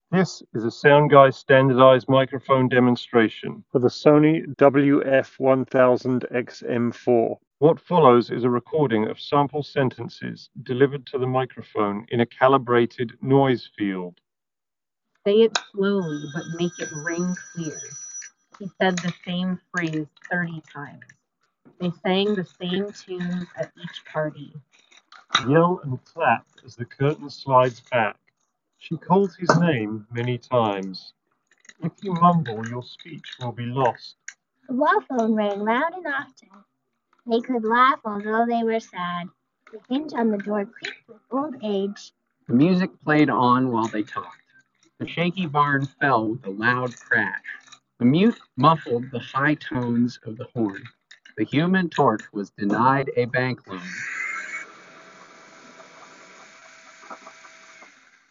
The Pixel Buds Pro microphones sound noticeably clearer since they emphasize high frequencies more than the Sony WF-1000XM4.
Most of our simulated office noises get transmitted by both these sets of earbuds, so noise suppression isn’t the best on either.
Sony WF-1000XM4 microphone demo (Office):
Sony-WF-1000XM4_Office-microphone-sample.mp3